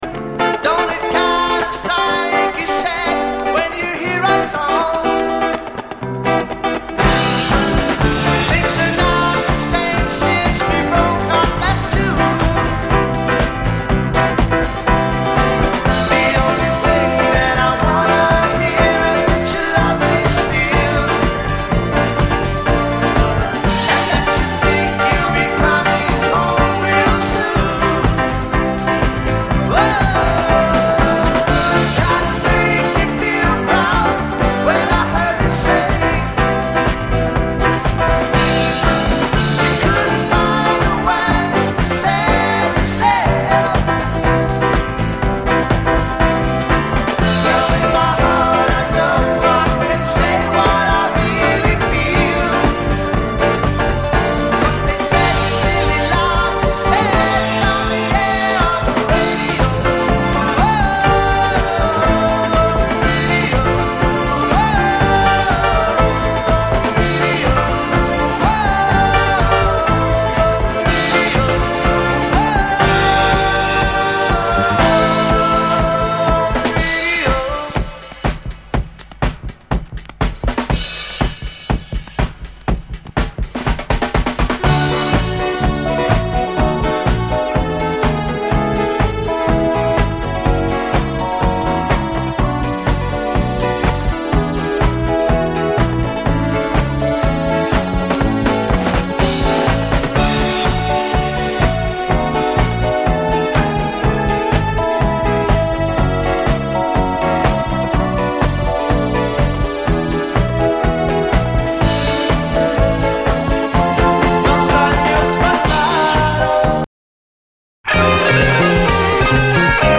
SOUL, 70's～ SOUL, DISCO, LATIN
哀愁系メロウ・ラテン・ロック